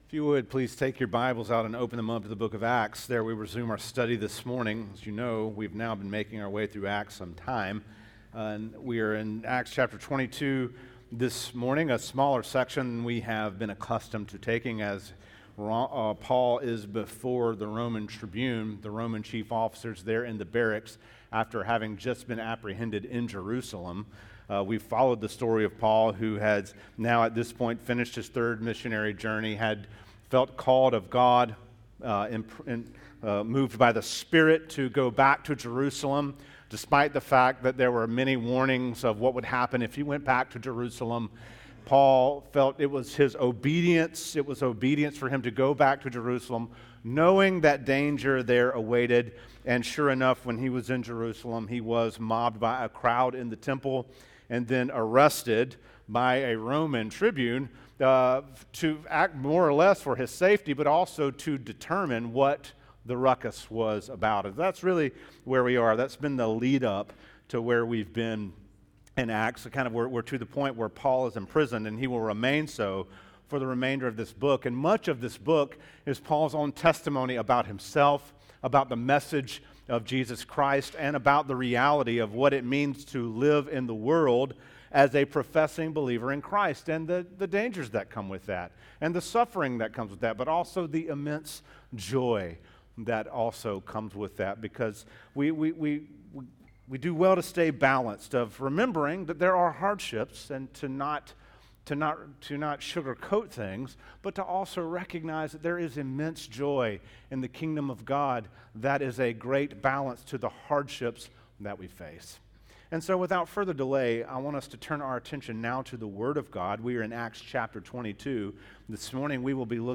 A Holy Shrewdness » The Chapel Church of Gainesville, Florida